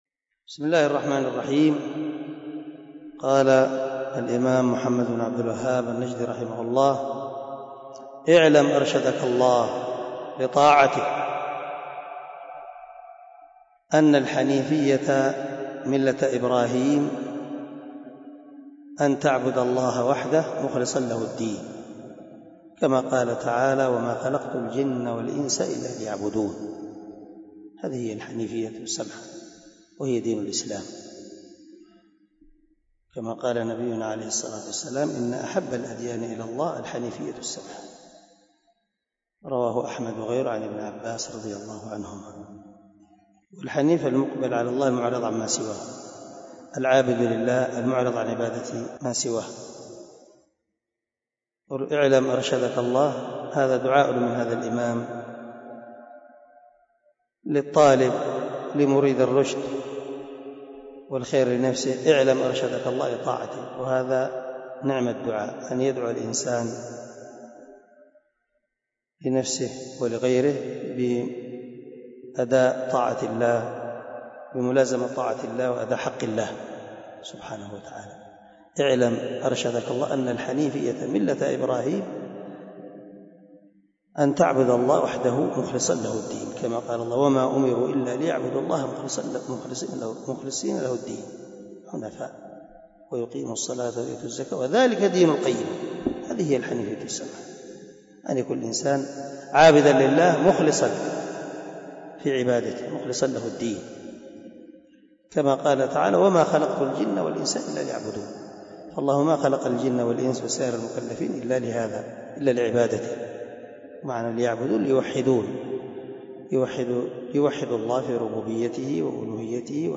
الدرس 2 من شرح القواعد الأربع
دار الحديث- المَحاوِلة- الصبيحة.